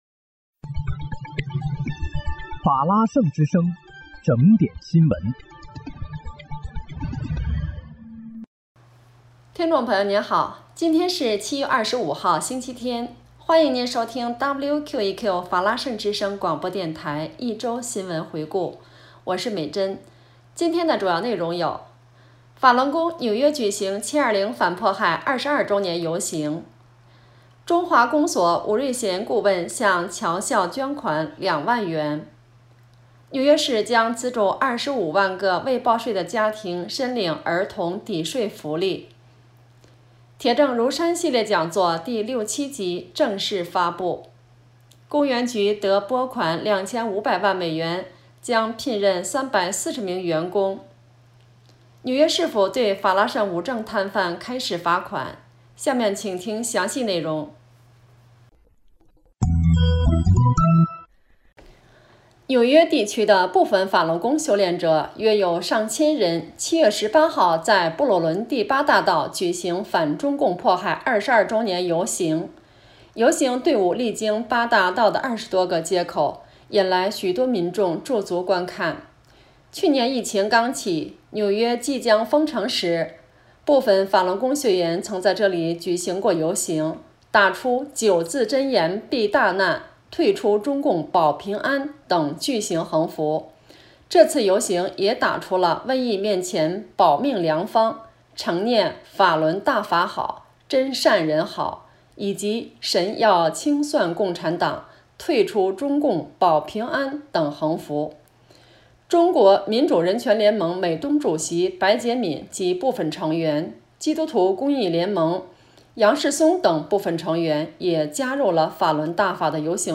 7月25日（星期日）一周新闻回顾